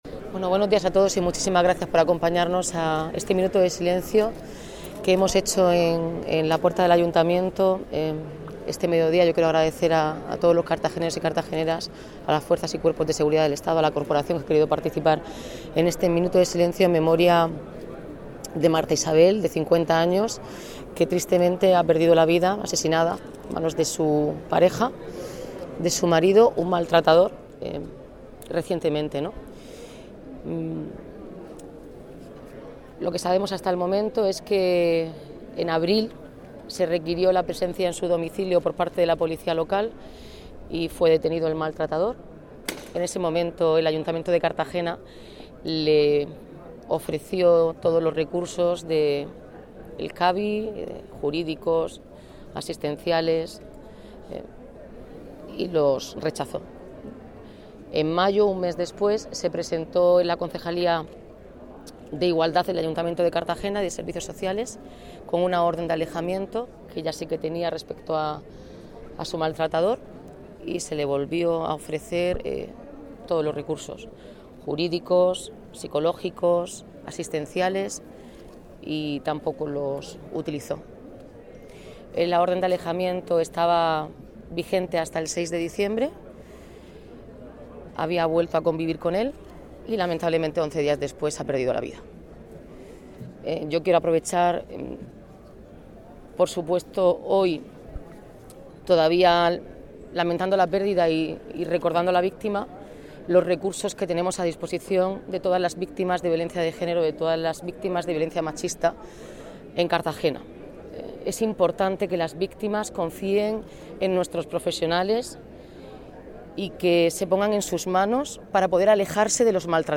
La alcaldesa, Noelia Arroyo, ha expresado su dolor por este suceso y ha aprovechado la ocasión para recordar “los recursos jurídicos y asistenciales que tenemos en el ayuntamiento, desdeIgualdad y Servicios Sociales, a disposición de las víctimas de violencia de género en Cartagena, es muy, muy importante que estas víctimas confíen en nuestros profesionales para poder alejarse de sus maltratadores”.